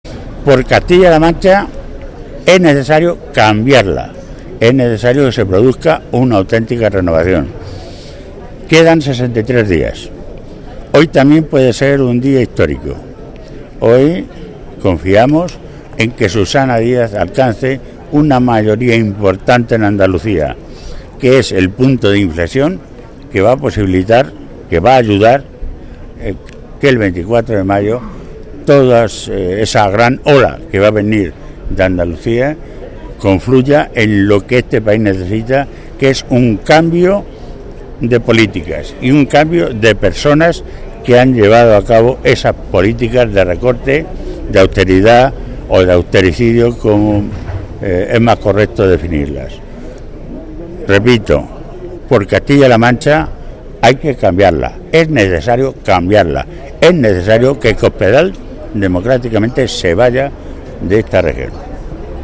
que realizó estas declaraciones momentos antes del inicio del Comité regional de los socialistas en la que se aprobaron tanto las listas a las elecciones autonómicas como la mayor parte de las listas municipales